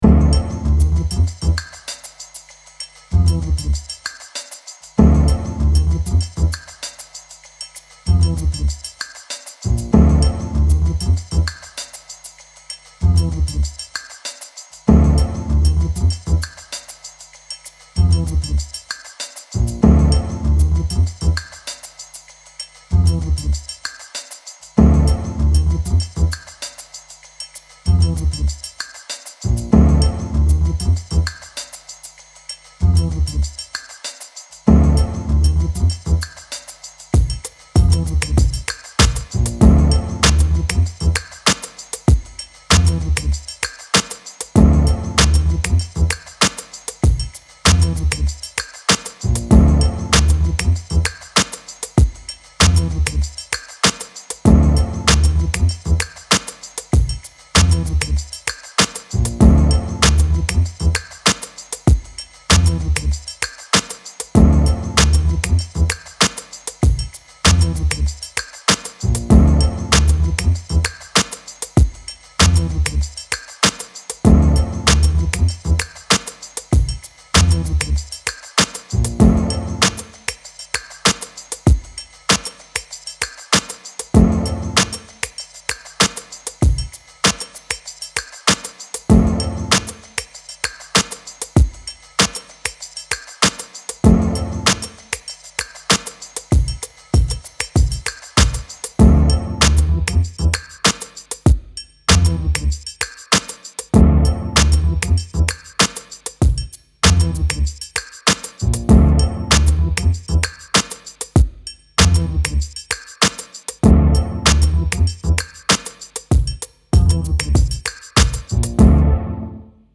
Sneaky espionage with cool hip hop beat.